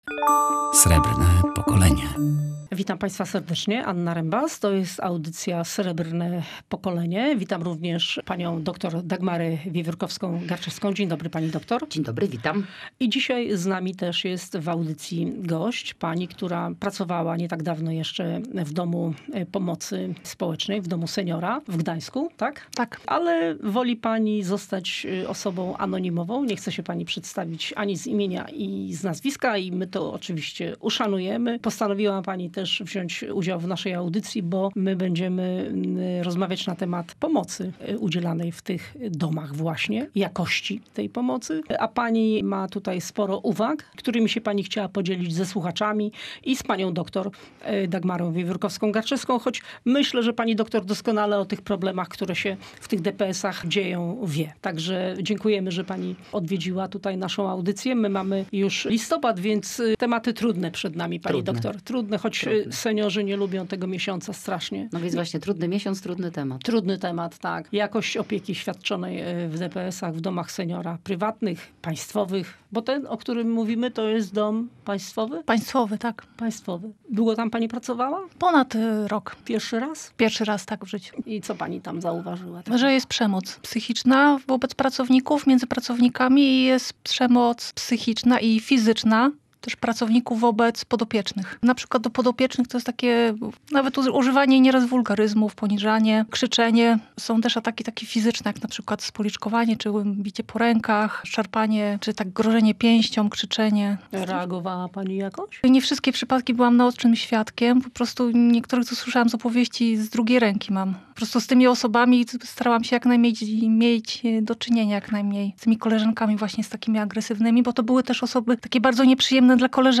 W programie wystąpiła też pracownica jednego z gdańskich DPS-ów, chcąca zachować anonimowość.